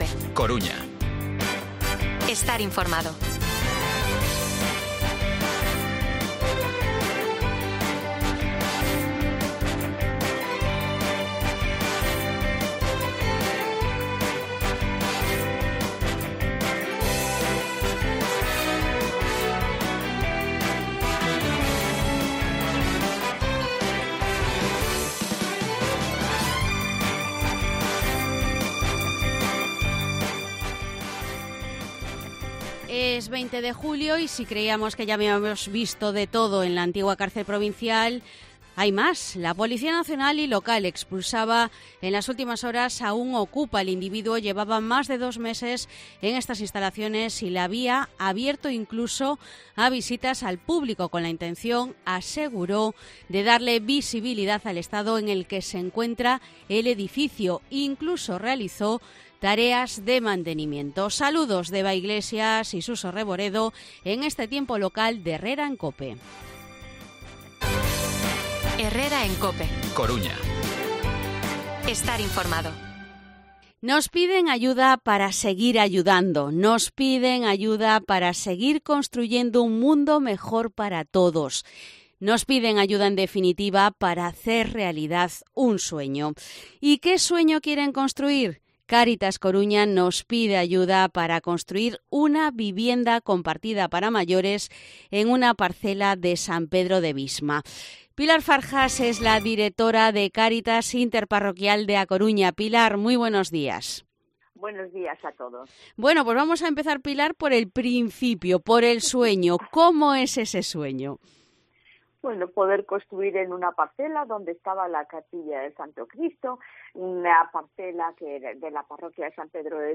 AUDIO: Desalojado okupa cárcel provincial. Entrevista